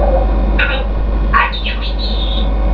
鳥たちのおしゃべり　＜２＞
33k エリちゃんによる遊びのお誘いです。